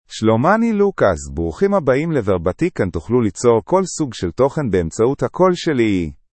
LucasMale Hebrew AI voice
Lucas is a male AI voice for Hebrew (Israel).
Voice sample
Listen to Lucas's male Hebrew voice.
Lucas delivers clear pronunciation with authentic Israel Hebrew intonation, making your content sound professionally produced.